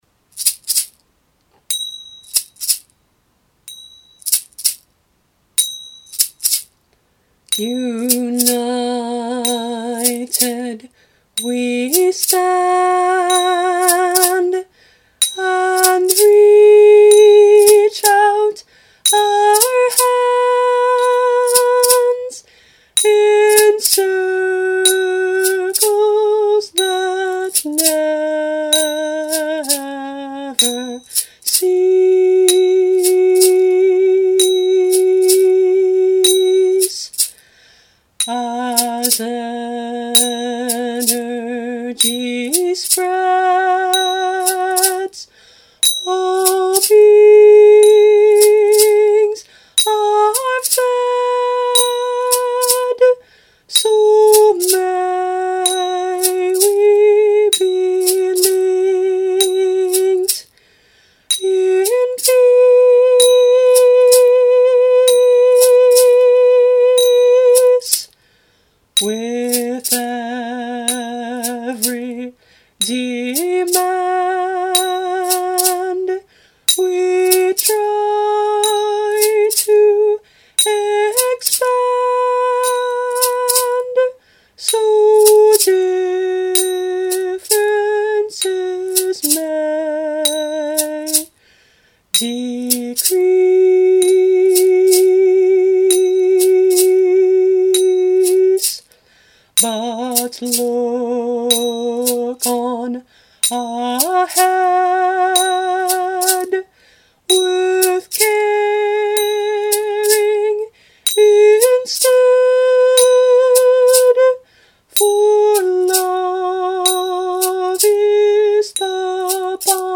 Instruments: Egg shaker, zills (finger cymbals)
This song is an alouette, like